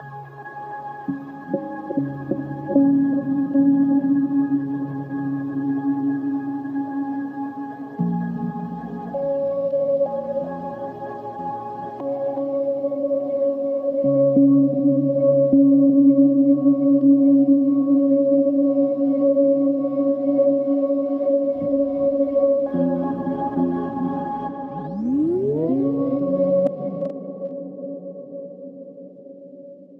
Bass Harmonic Ambience .wav